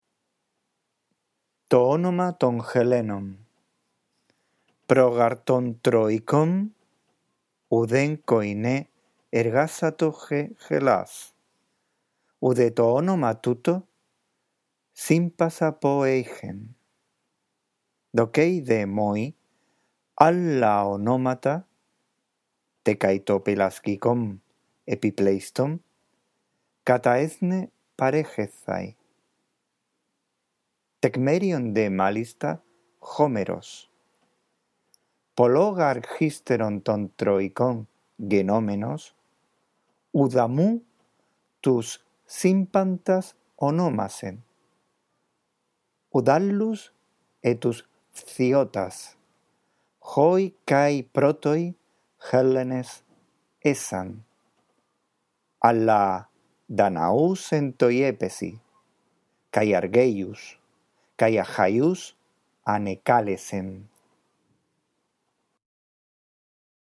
1. Lee, en primer lugar, despacio y en voz alta el texto.